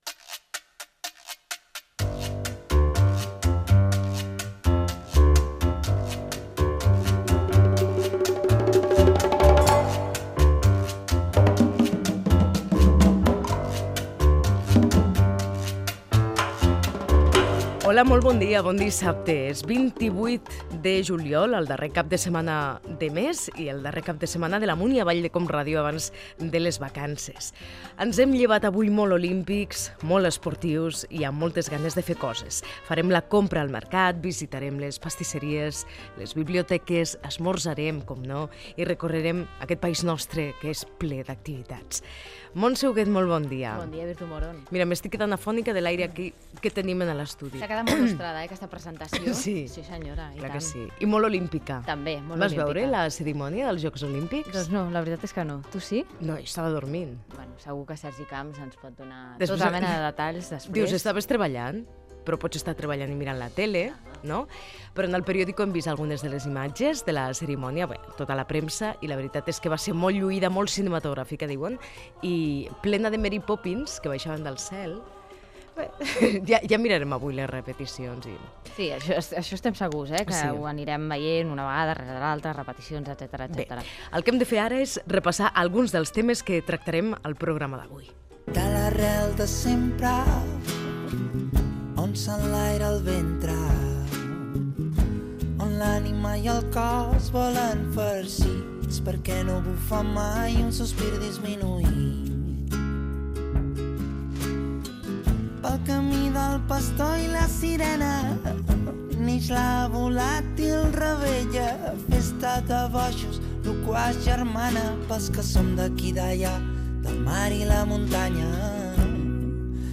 Sintonia, data, presentació, sumari del programa i comentari de la cerimònia d'inauguració dels Jocs Olímpics de Londres
Entreteniment
Fragment extret de l'arxiu sonor de COM Ràdio.